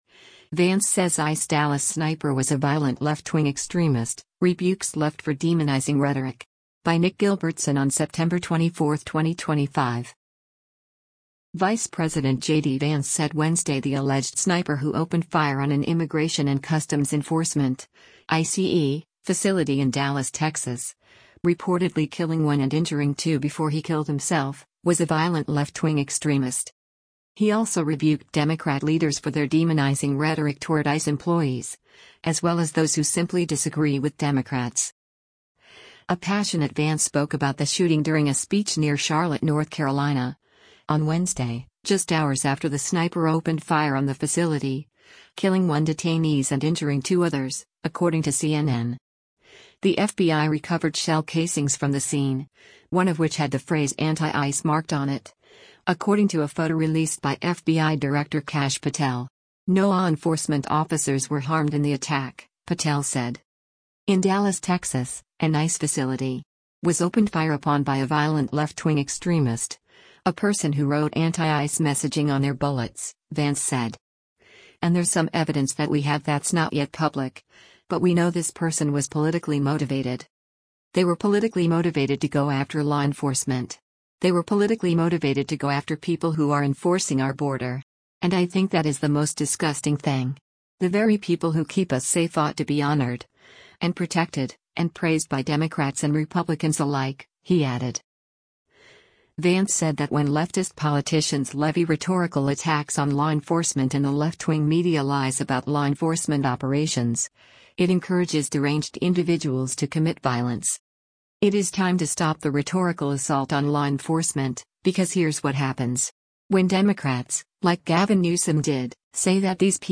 A passionate Vance spoke about the shooting during a speech near Charlotte, North Carolina, on Wednesday, just hours after the sniper opened fire on the facility, killing one detainees and injuring two others, according to CNN.